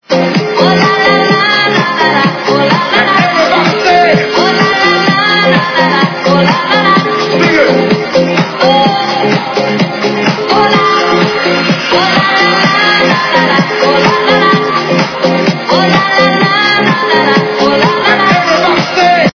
- Remix
качество понижено и присутствуют гудки